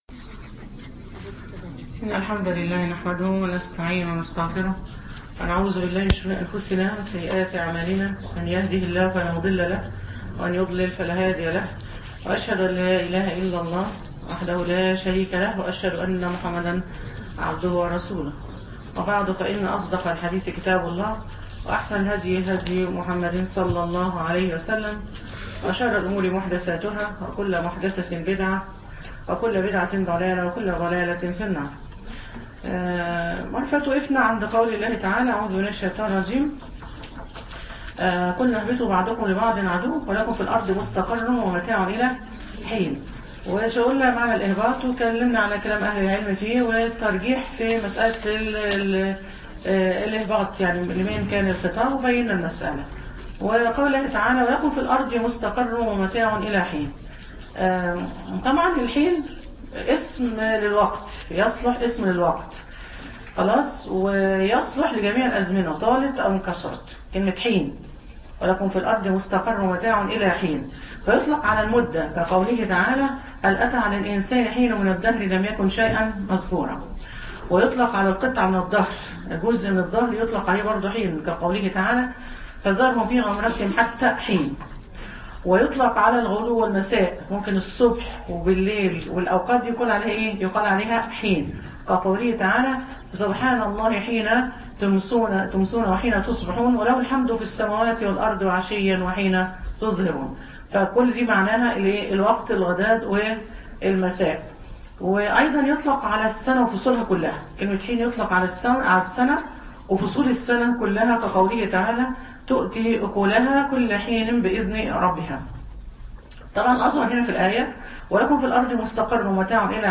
تدبر سورة البقرة المحاضرة الثامنة من آية (36-41)